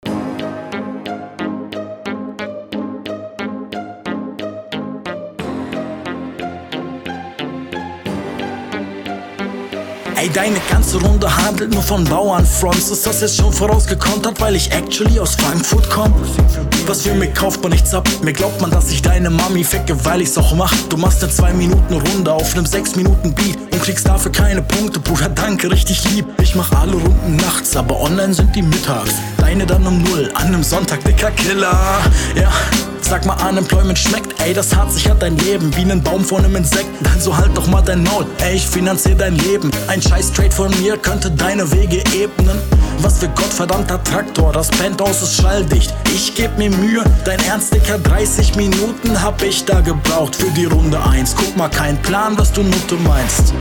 Finde den Flow hier schwächer als in deiner Hinrunde, vielleicht liegt das wirklich am Beattempo.